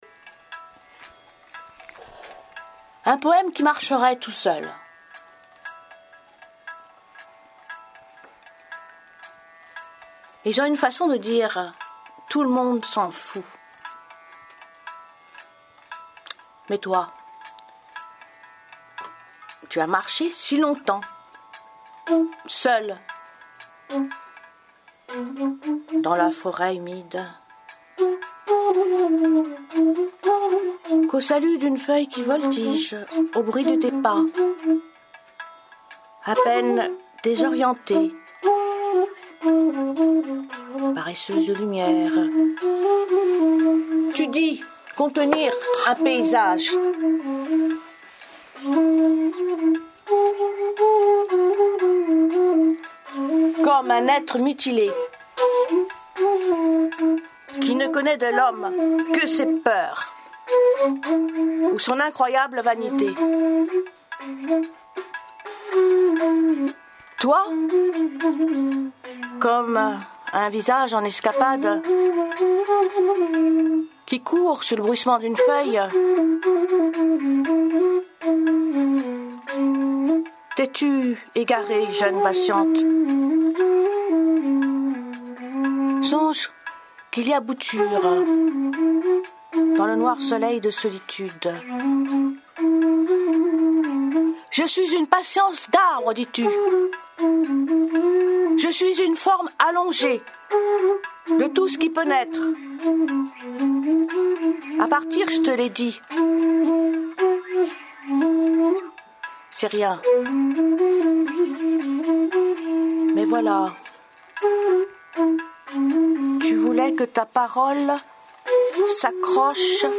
Improvisation musicale
(Flûte indienne Bansuri et trompette), émission DEUX SOUS DE SCENE RADIO LIBERAIRE 25 AOUT 2012)